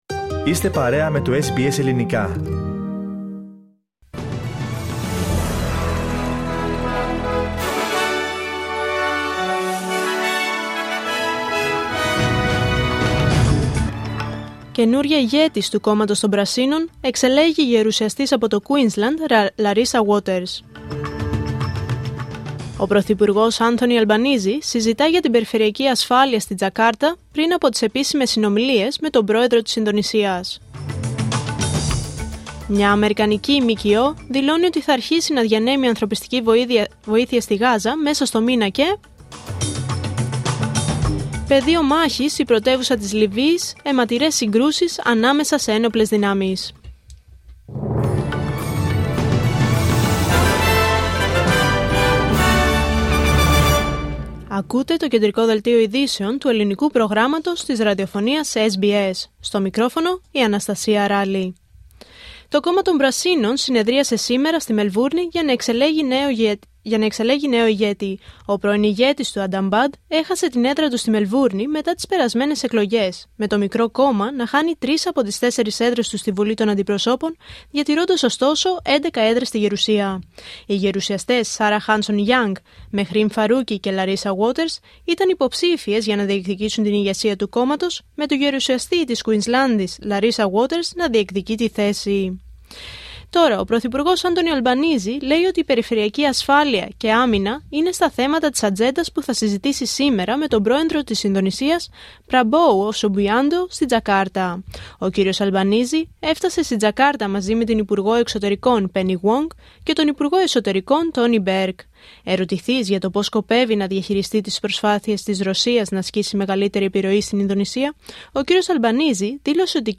Δελτίο Ειδήσεων Πέμπτη 15 Μαΐου 2025